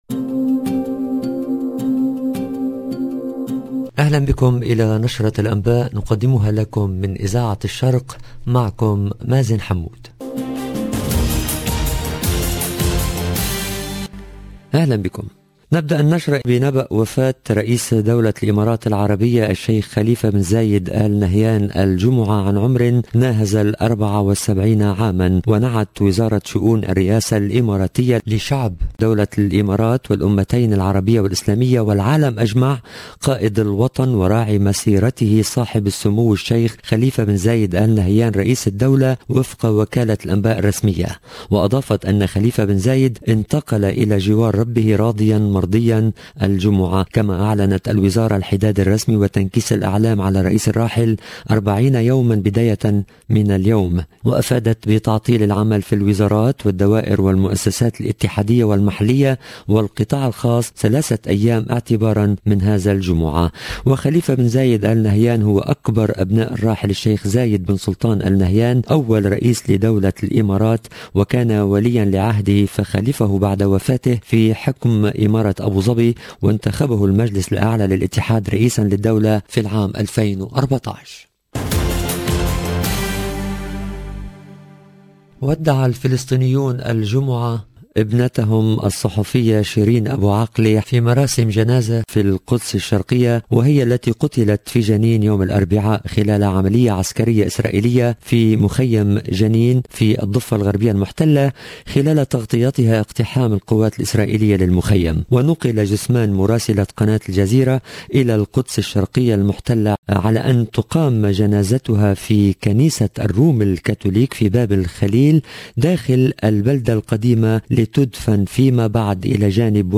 LE JOURNAL DU SOIR EN LANGUE ARABE DU 13/05/22